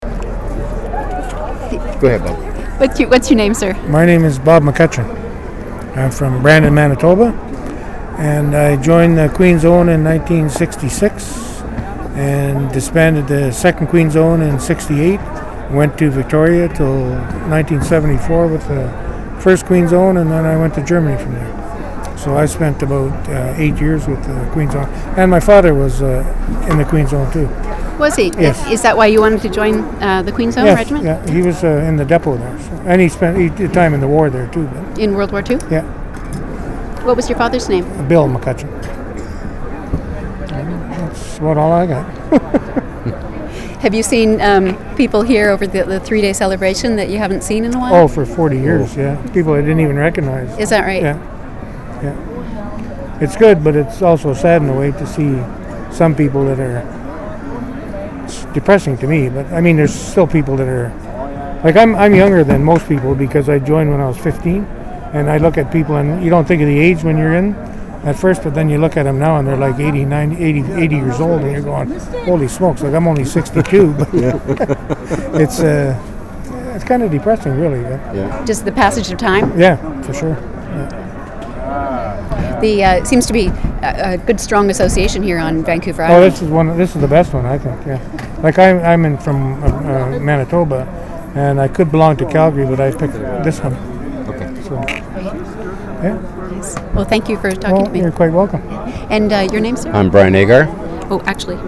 Interviewer
Interviewee
• Interview took place during the Queen's Own Rifles of Canada Vancouver Island Branch 150th Anniversary Celebration.